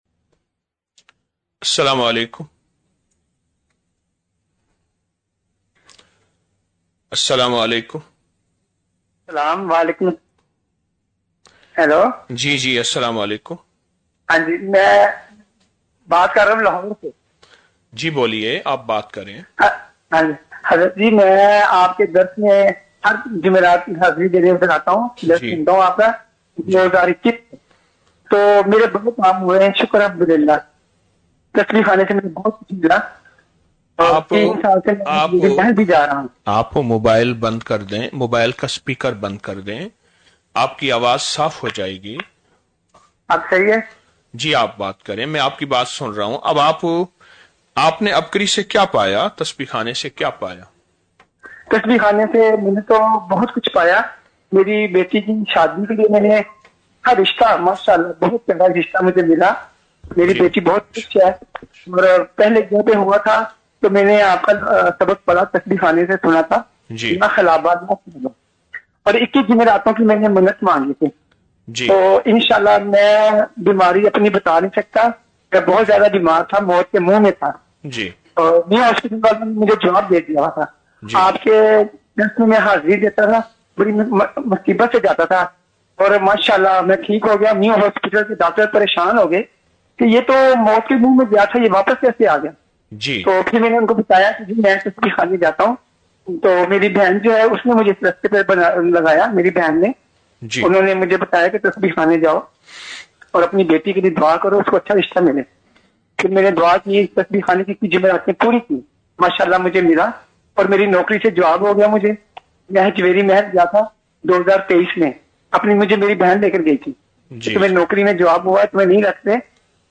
|| 20 رمضان المبارک بعد نماز فجر- 10 مارچ 2026ء